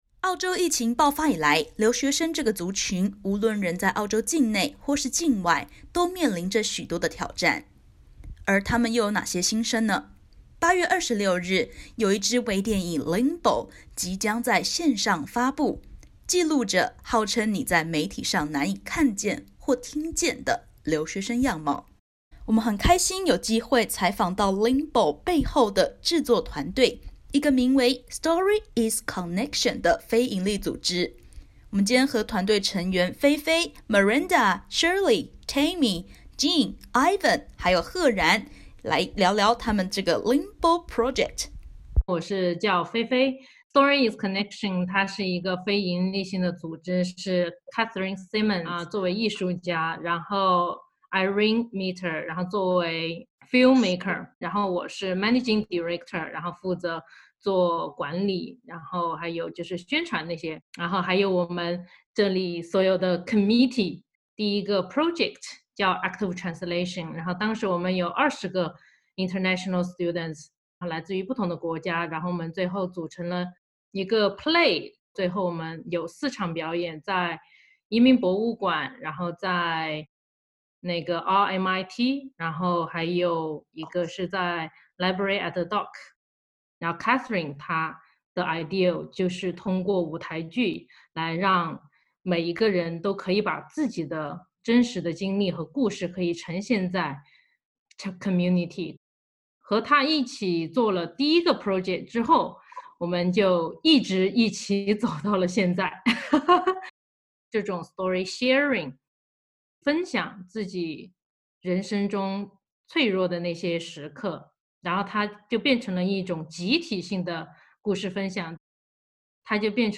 澳洲疫情爆发以来，留学生在媒体上的形象，大多是脆弱无助，一支汇集世界各地、21名留学生故事的微电影《LIMBO》，可能会颠覆想像。点击图片收听完整采访音频。